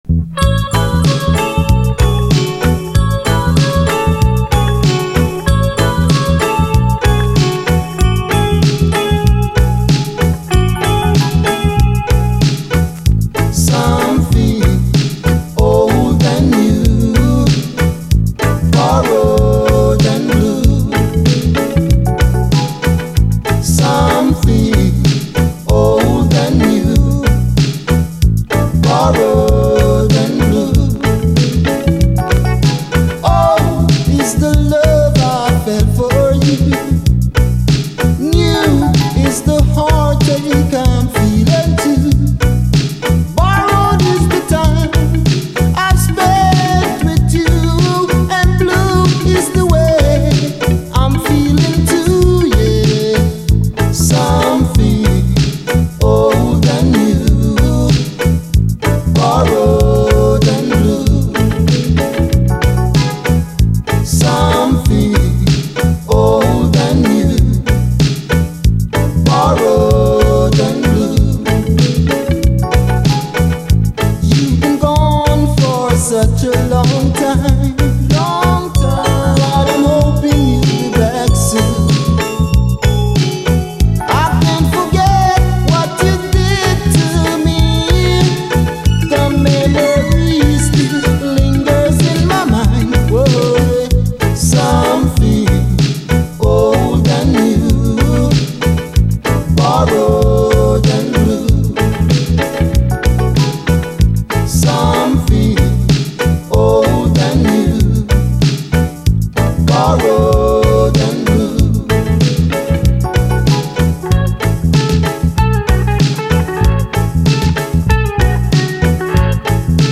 REGGAE
南国ムード香るUKラヴァーズ・シングル！
ギター・プレイが光る南国ムードのリラクシン・ラヴァーズ！B面はインスト・ヴァージョン。